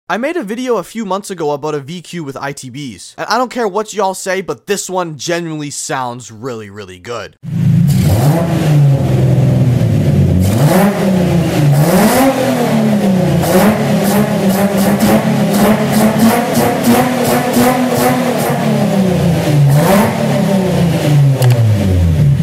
RAW SOUND VQ35 ITBS SOUNDS sound effects free download